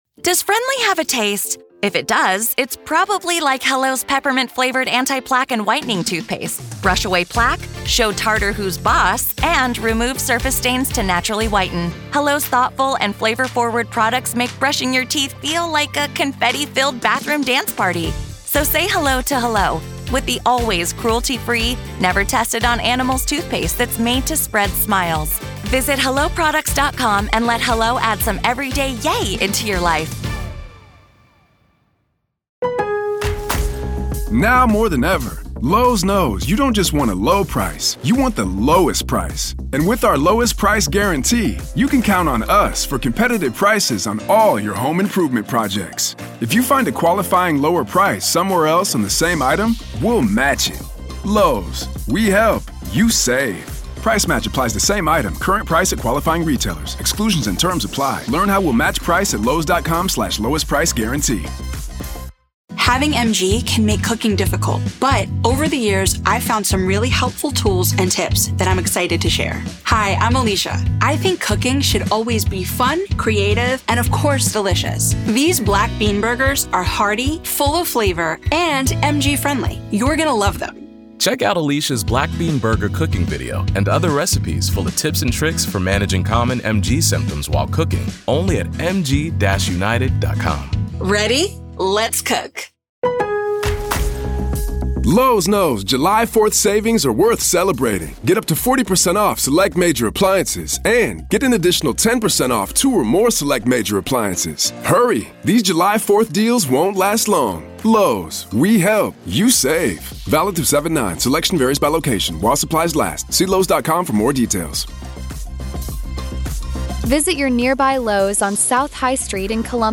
Former felony prosecutor